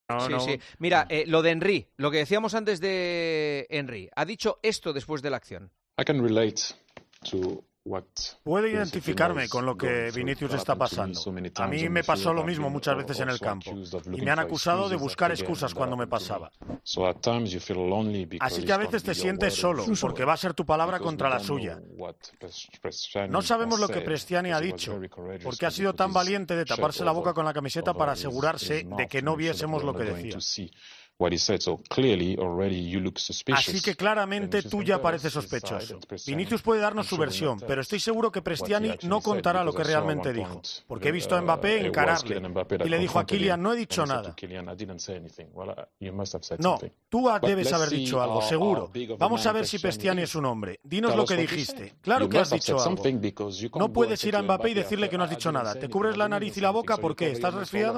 Thierry Henry habla sobre el episodio racista durante el Benfica - Real Madrid con Vinicius y Prestianni